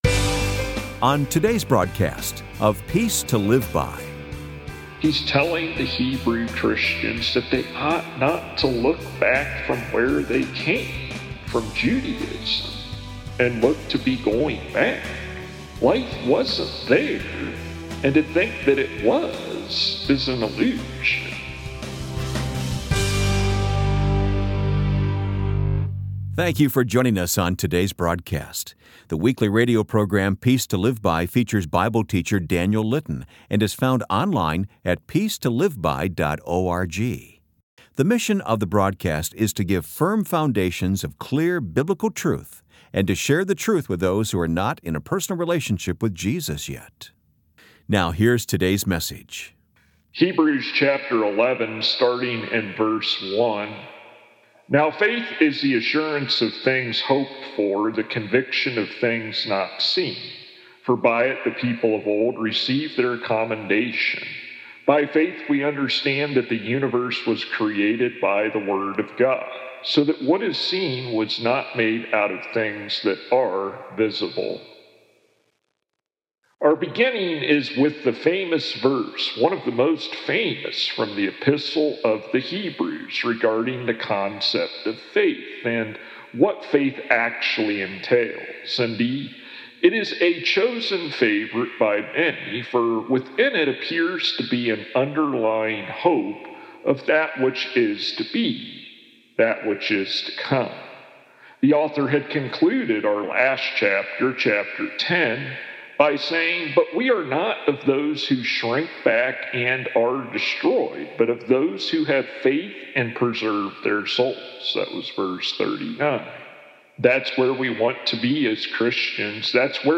For full sermons without edits for time, tap here to go to downloads page.